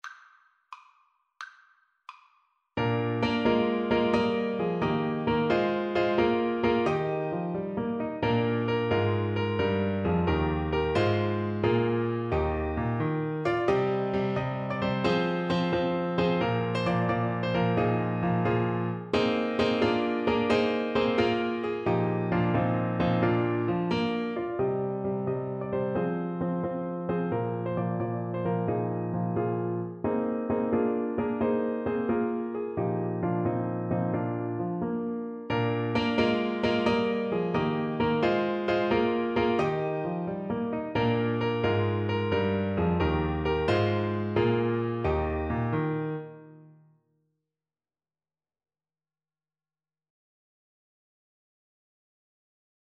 6/8 (View more 6/8 Music)
Traditional (View more Traditional Clarinet Music)